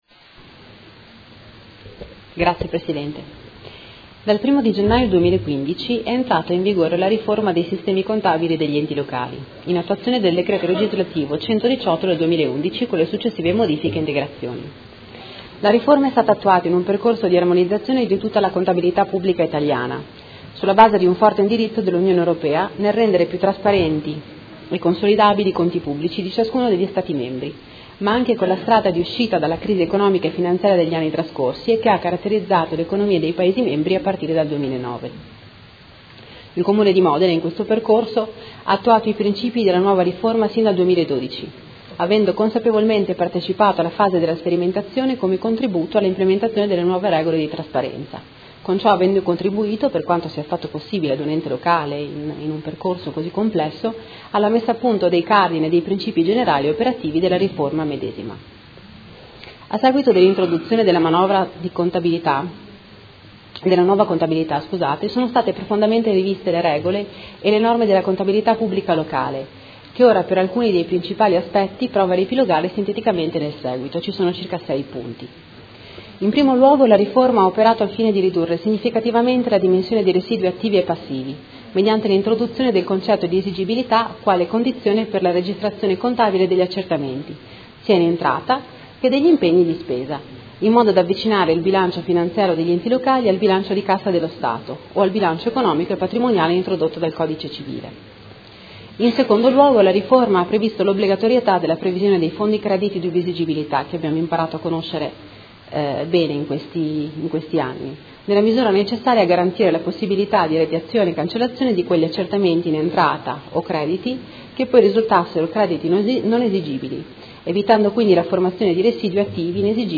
Ludovica Ferrari — Sito Audio Consiglio Comunale
Seduta del 24/11/2016 Delibera. Approvazione Regolamento di contabilità del Comune di Modena